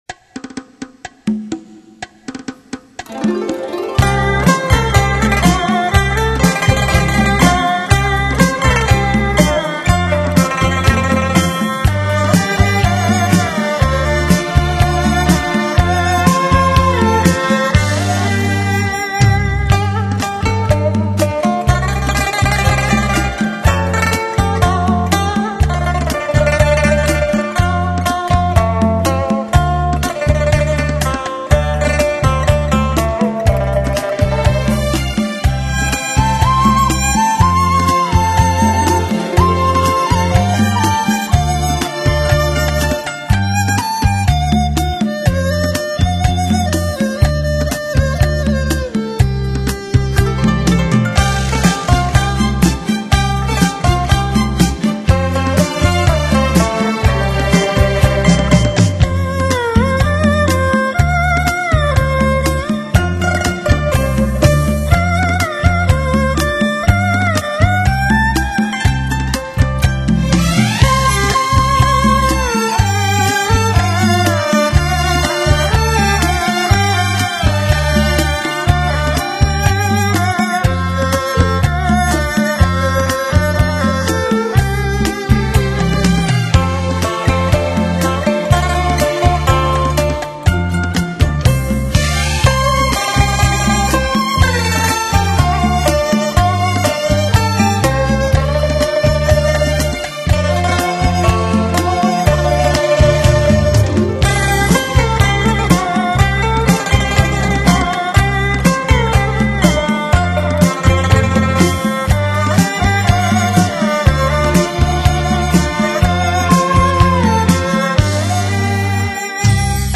补缺]中国轻音乐
让那充满感情，气氛怀旧动人的优美乐曲，
试听曲为低品质wma，下载为320k/mp3